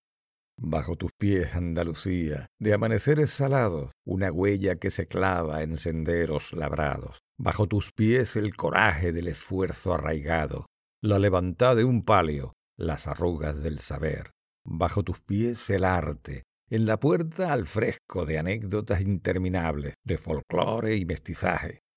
Locutor autónomo con estudio de audio Micro Neumann TLM 103 Interface Focusrite Clarett 2pre Auriculares Beyerdinamic DT 770 PRO Cabina.
Sprechprobe: eLearning (Muttersprache):
Español acento andaluz.mp3
Español acento andaluz_1.mp3